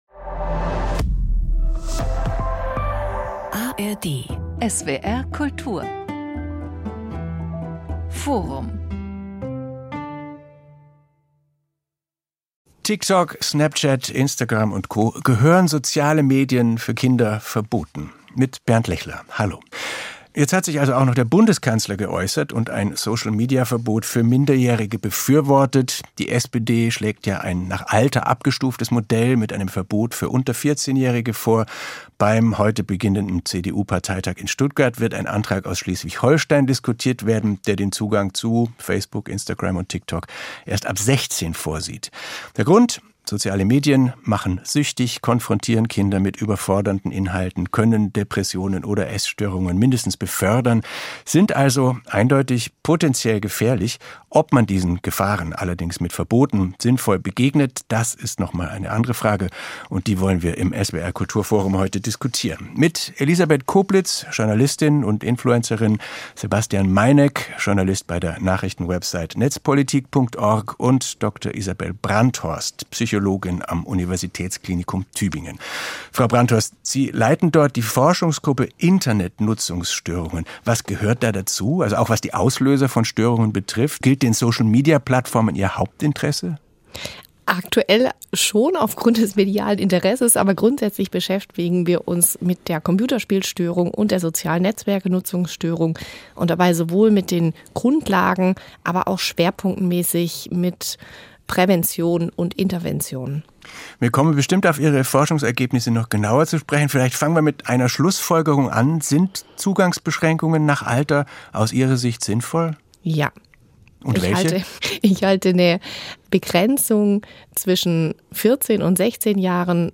Journalistin und „Newsfluencerin“